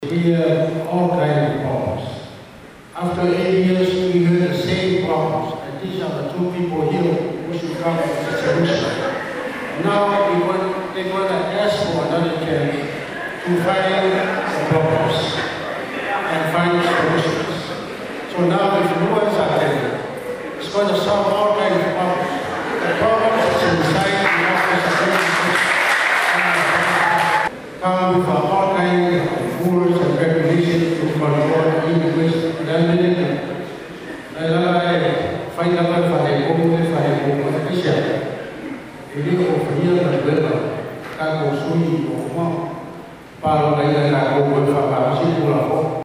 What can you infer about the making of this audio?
That’s one of the questions at the ASCC Gubernatorial Forum last week.